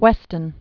(wĕstən), Edward 1886-1958.